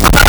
Player_Glitch [11].wav